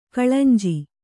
♪ kaḷanji